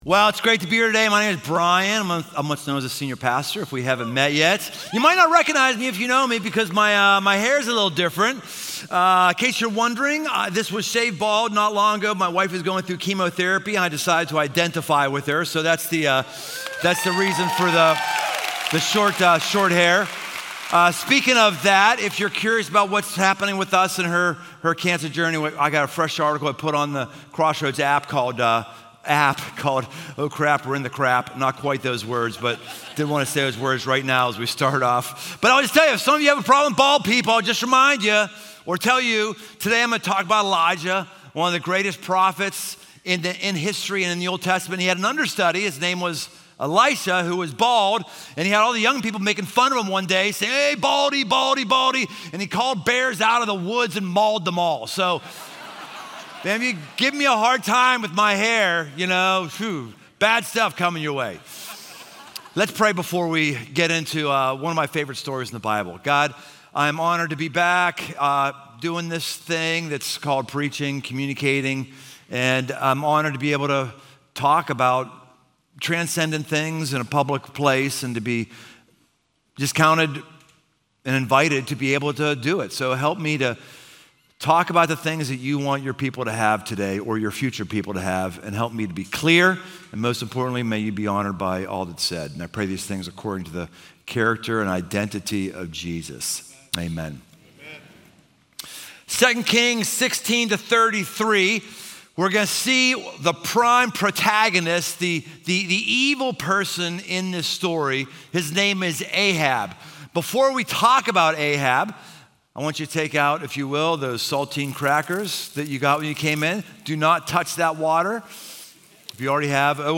Recorded live at Crossroads Oakley in Cincinnati, Ohio.